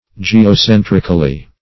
Geocentrically \Ge`o*cen"tric*al*ly\, adv.
geocentrically.mp3